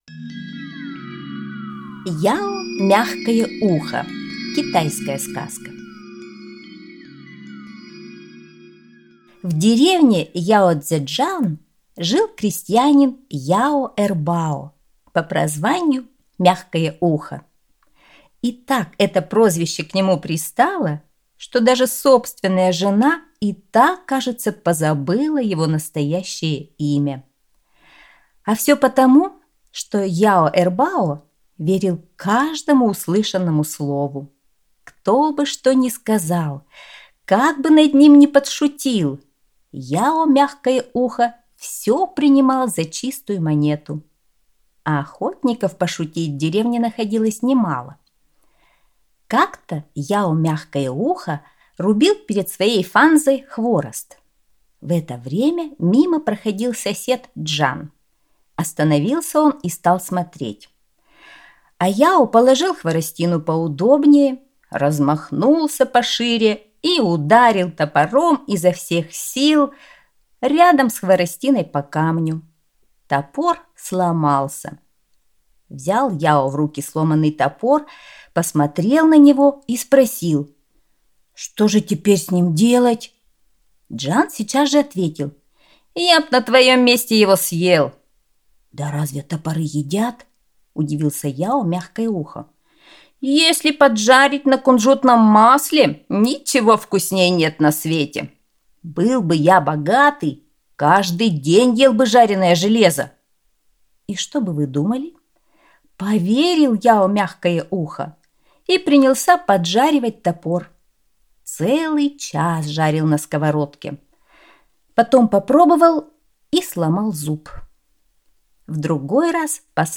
Яо-Мягкое ухо - китайская аудиосказка. Поучительная сказка о том, что не надо верить всему, что говорят люди…